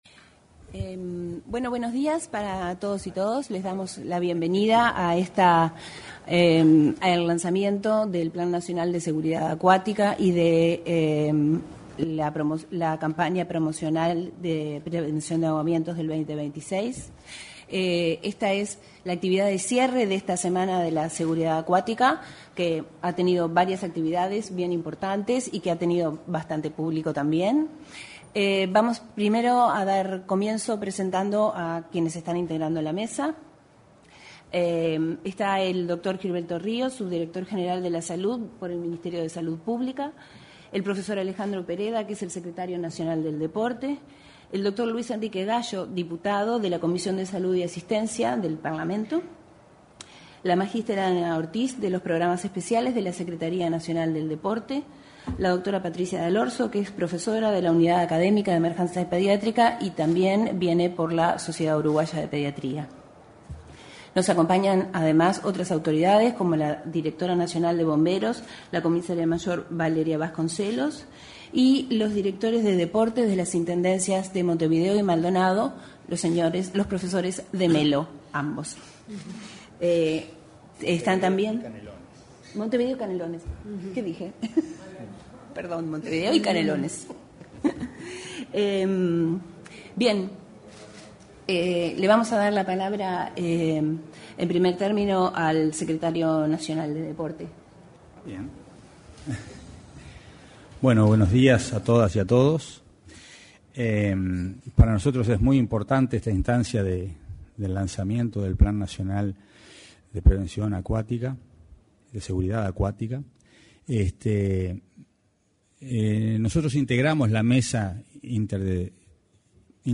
Presentación del Plan Nacional de Seguridad Acuática 07/11/2025 Compartir Facebook X Copiar enlace WhatsApp LinkedIn La Secretaría Nacional del Deporte y el Ministerio de Salud Pública presentaron el Plan Nacional de Seguridad Acuática, en el salón de actos de la Torre Ejecutiva. Participaron en la apertura el secretario nacional del Deporte, Alejandro Pereda, y el subdirector general de Salud, Gilberto Ríos.